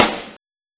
SNARE134.wav